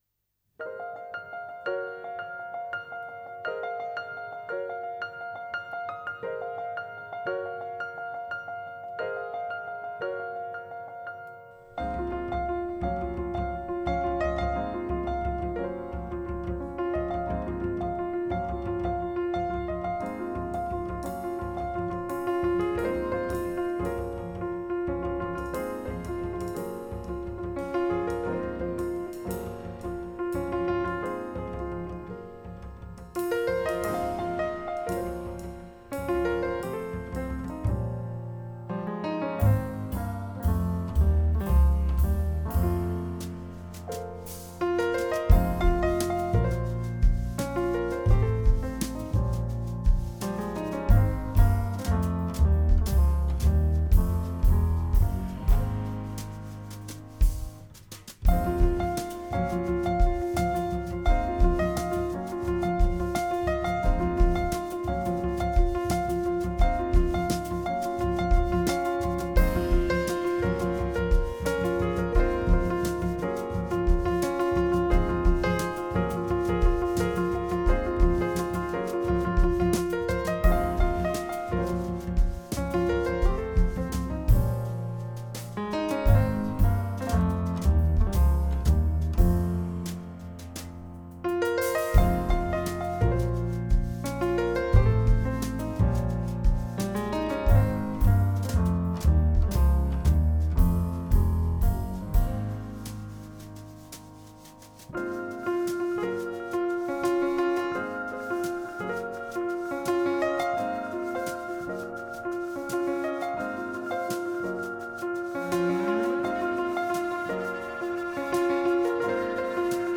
clavier
batterie
contrebasse